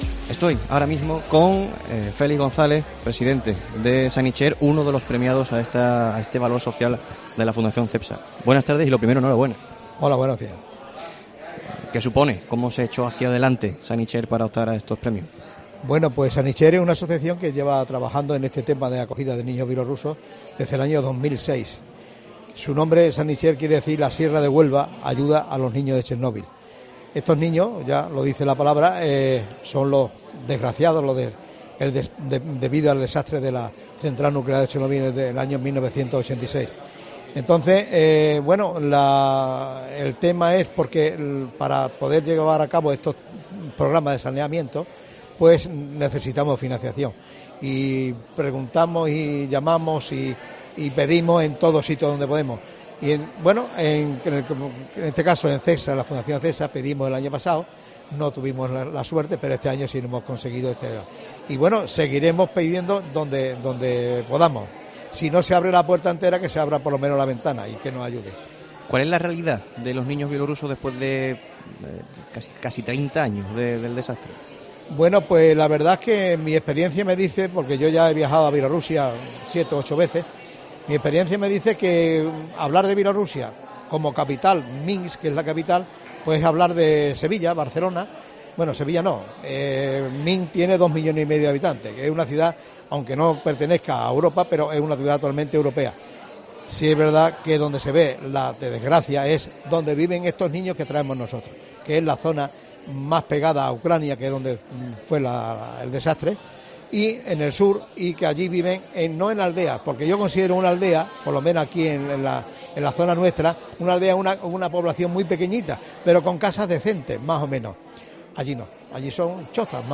El tiempo local de Mediodía COPE se ha realizado hoy en la 15ª edición de los premios al Valor Social que ha entregado la Fundación Cepsa.